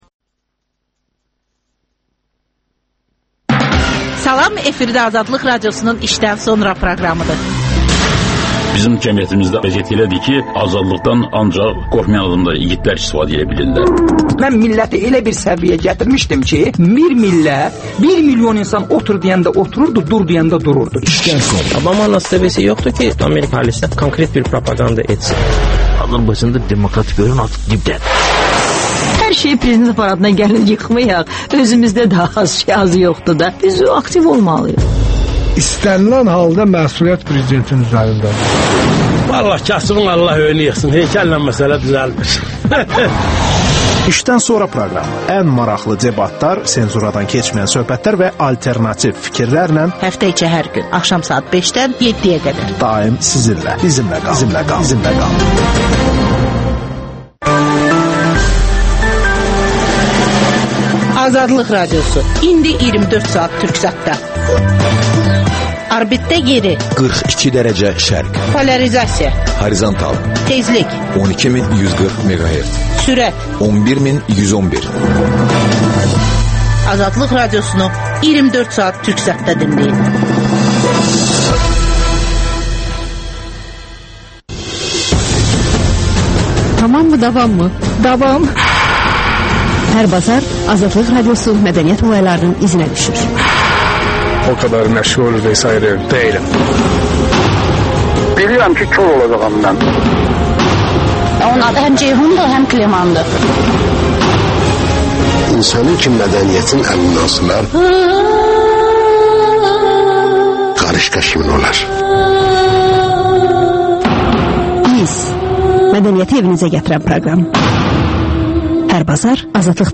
Müəlliflə müsahibə